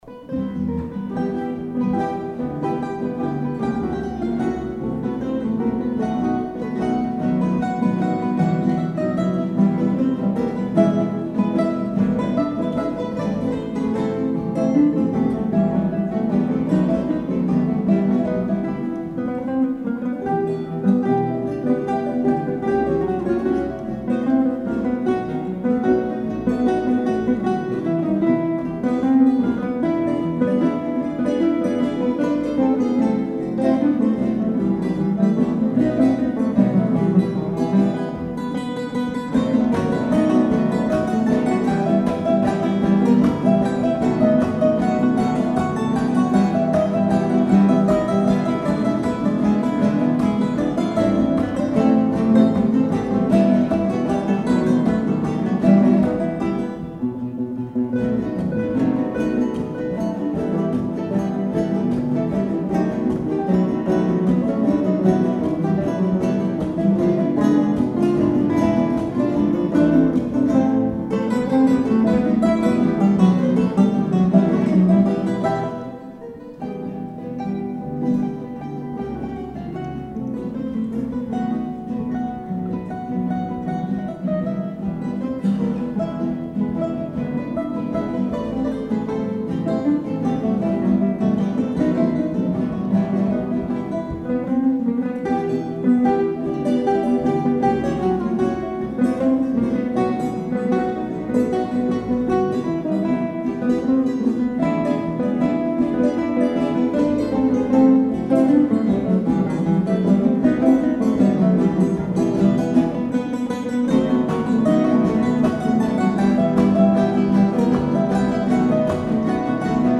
Hampshire Guitar Orchestra - our living history - 2012
hago in Bedhampton